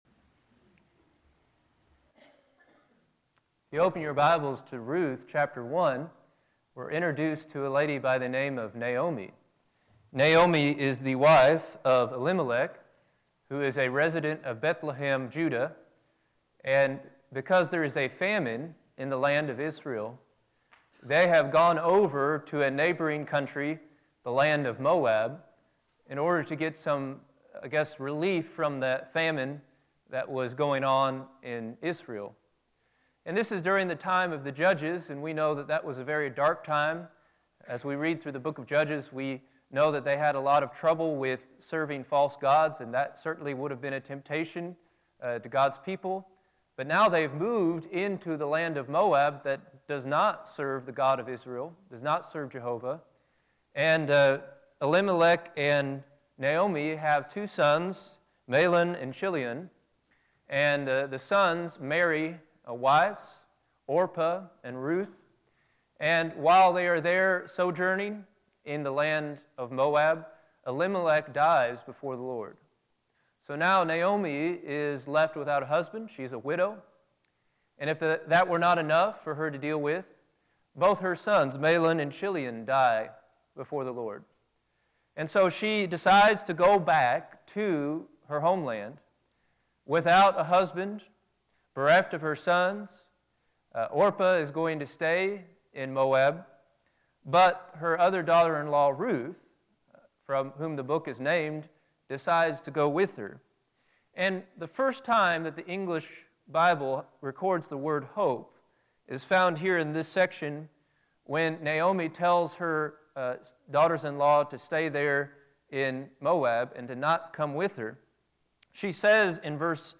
Filed Under: Featured, Lesson Audio